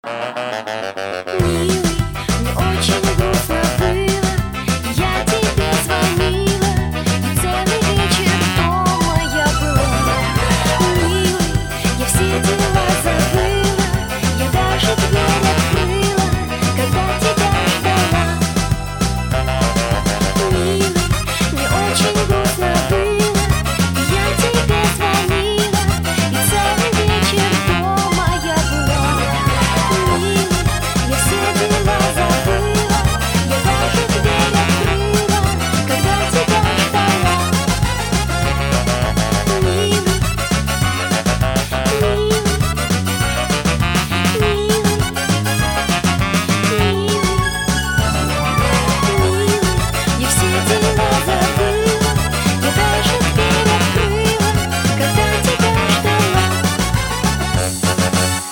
• Качество: 192, Stereo
поп
80-е